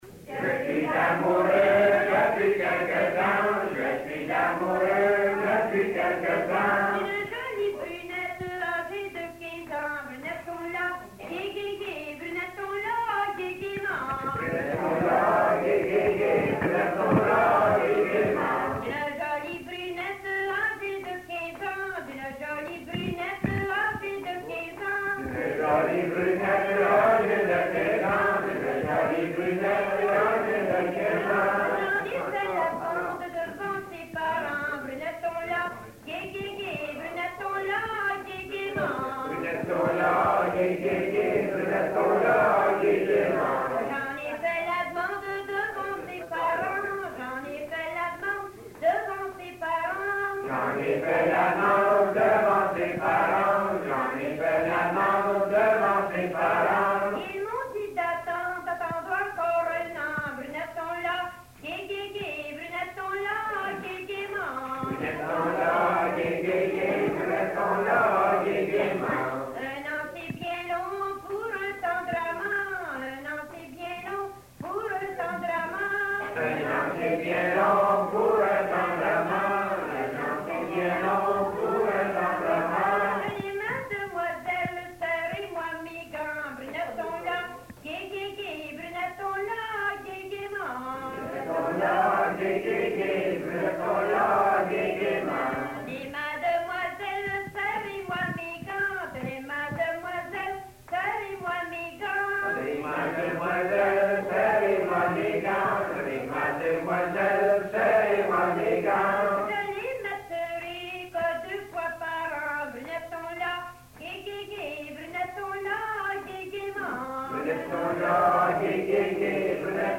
Folk Songs, French--New England
Song